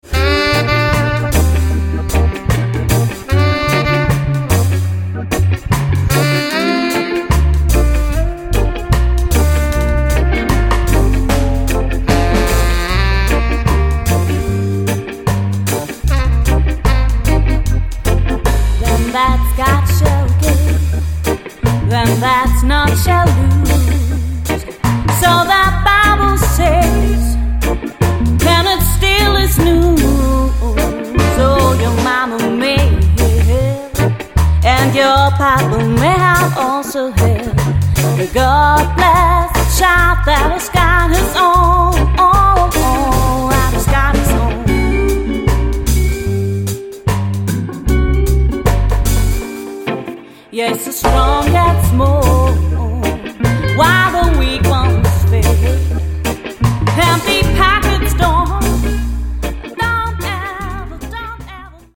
Reggae, Rocksteady, Jamaican Jazz mit Dancehall Kicks